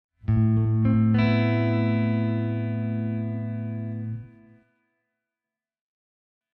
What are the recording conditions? My solution is to set up a bunch of instances of LA convolver on multiple busses in AU LAB. Here are the IR’s used in bus 2: